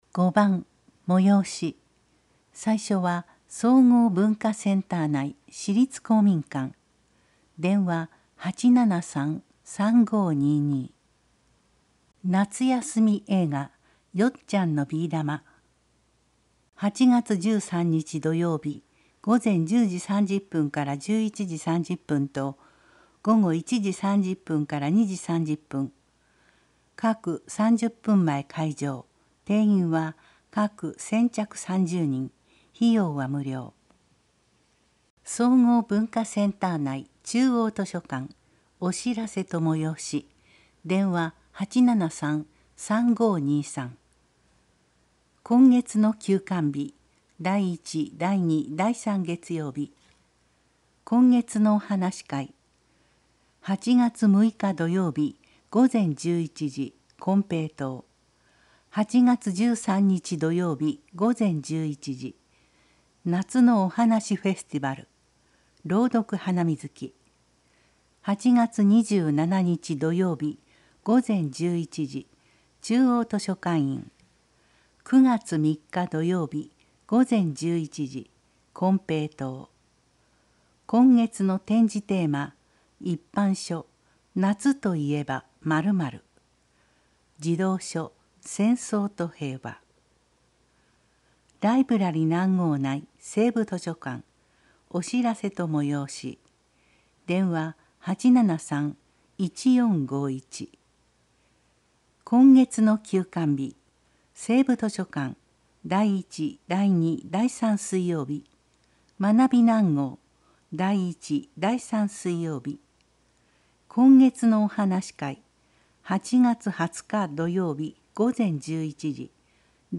声の広報「だいとう」令和4年8月号を掲載しています。